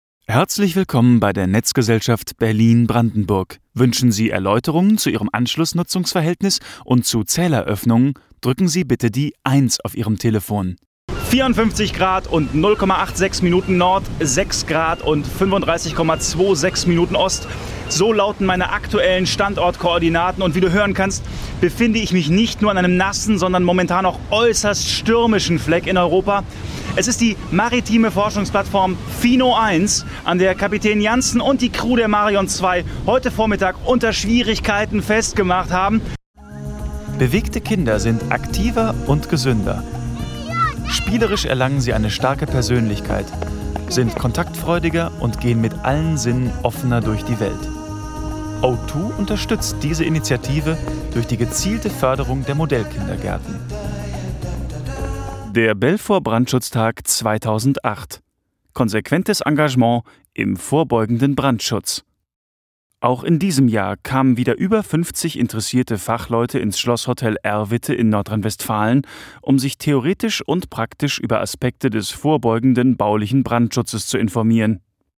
Sprecher deutsch. Frische, klare, direkte Stimme.
Sprechprobe: eLearning (Muttersprache):
voice over artist german